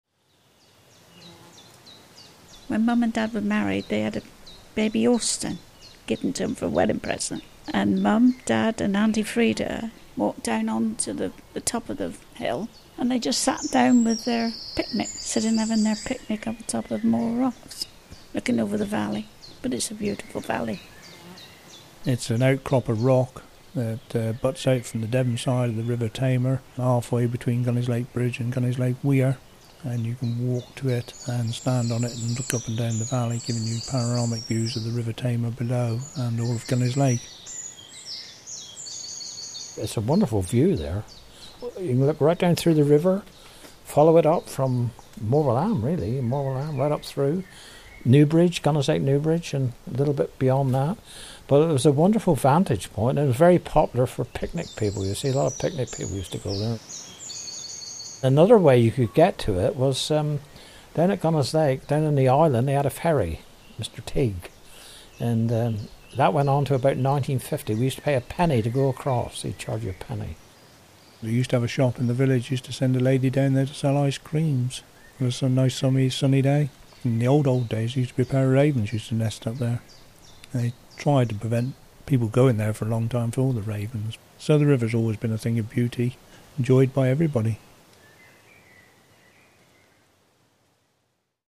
Listen to locals share memories from the past.
Listen to local people recall fond memories of days out at Morewell Rocks.